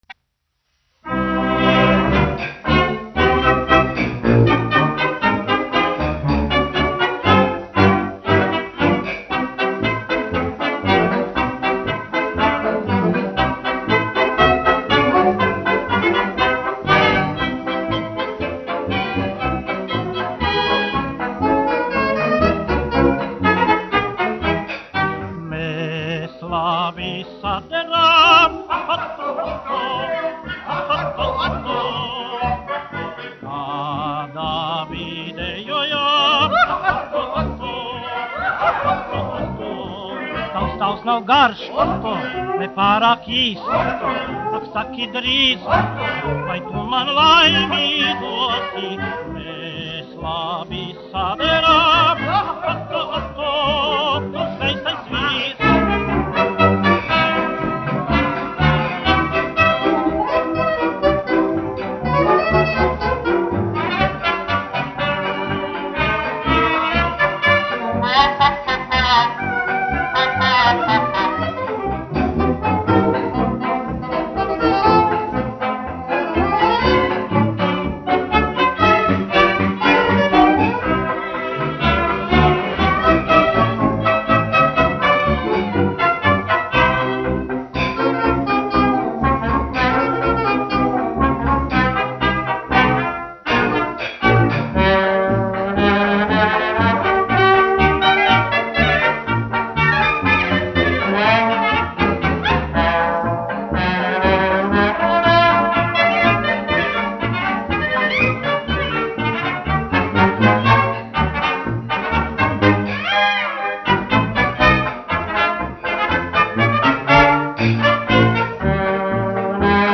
1 skpl. : analogs, 78 apgr/min, mono ; 25 cm
Fokstroti
Populārā mūzika
Skaņuplate
Latvijas vēsturiskie šellaka skaņuplašu ieraksti (Kolekcija)